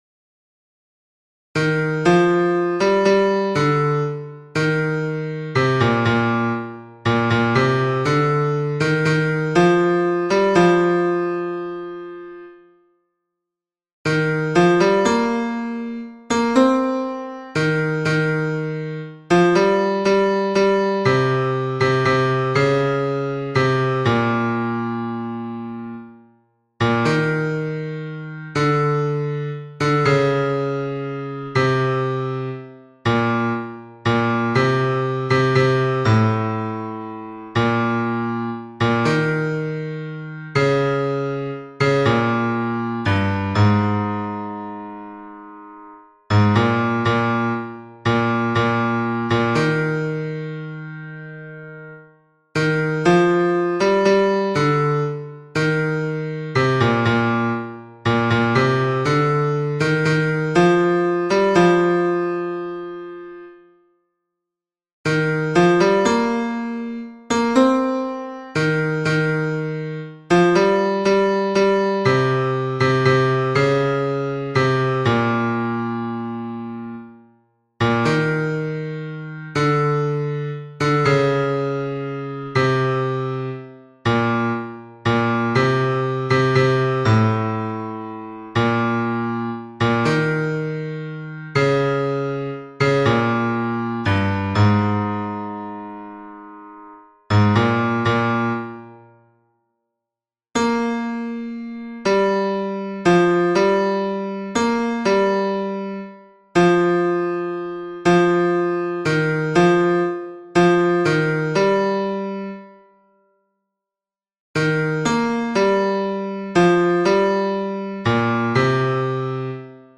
basses-mp3 18 octobre 2020